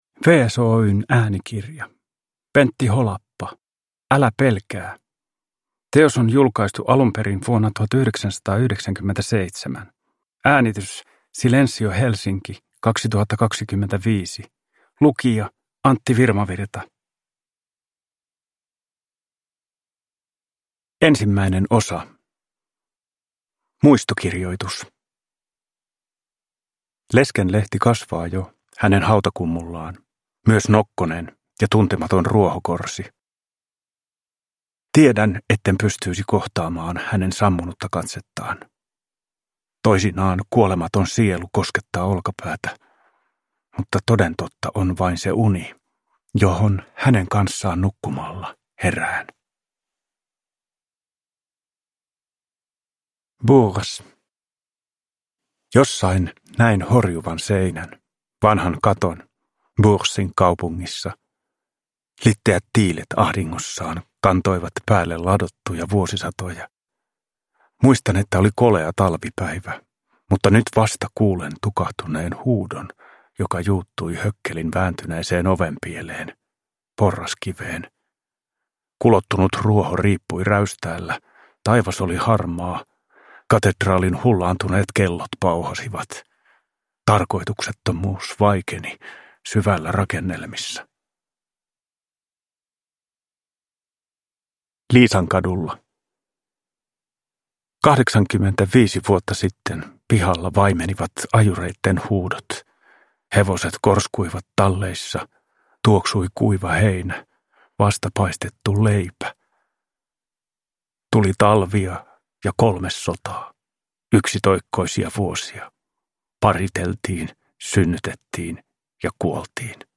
Älä pelkää! – Ljudbok
Uppläsare: Antti Virmavirta